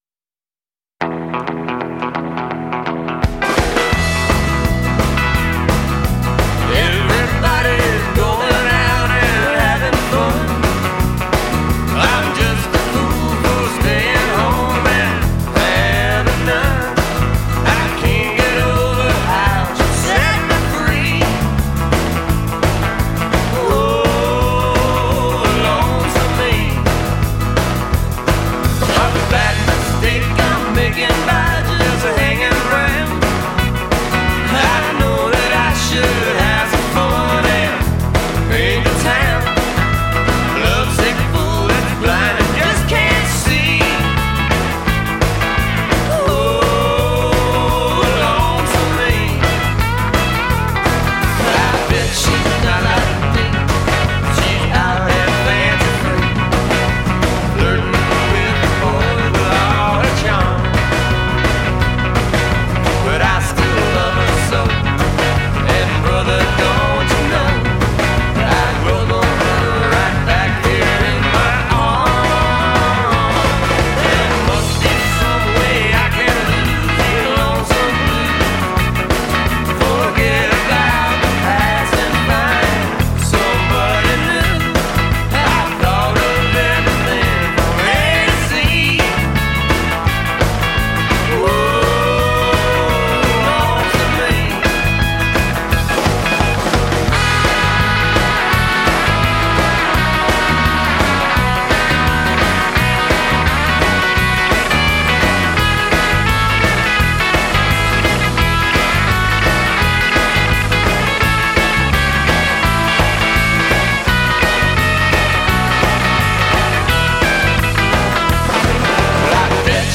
hillbilly surf rock
toe-sucking geek rock